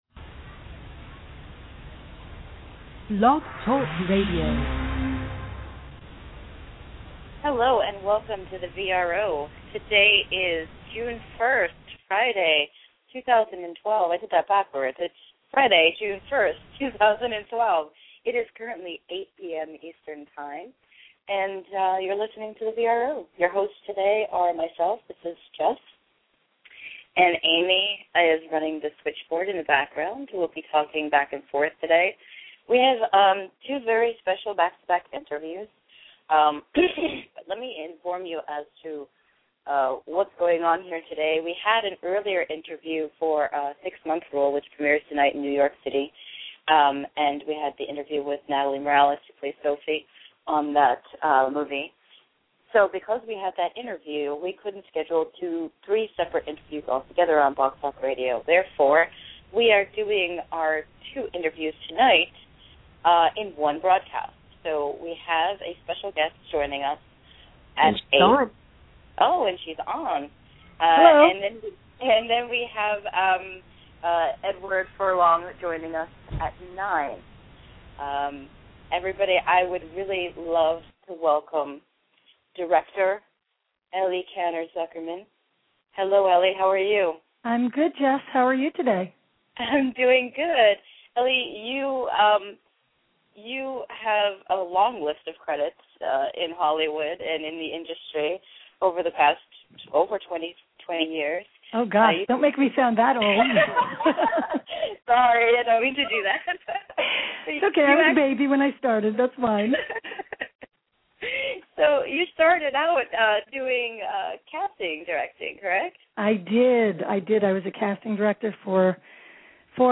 Edward Furlong - Interview